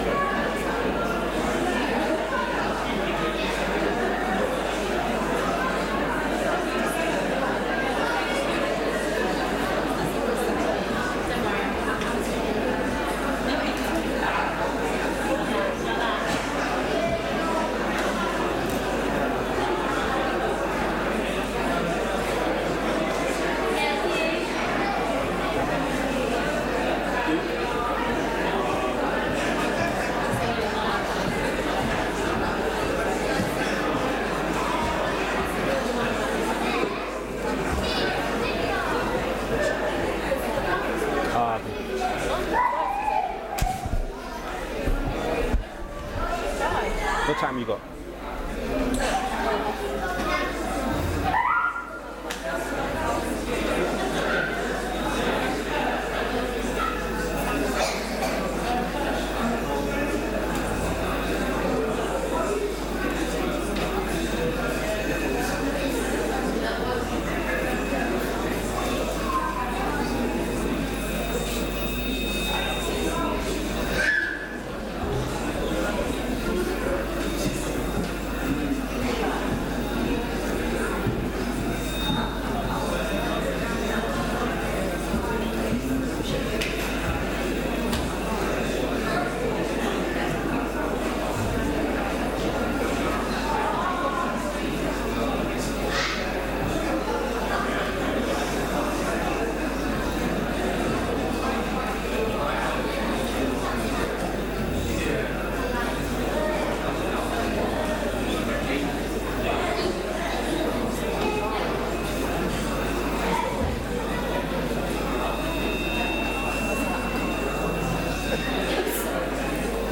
Join us for our annual candlelit carol service - this year the theme is 'All I want for Christmas is?'
Service Audio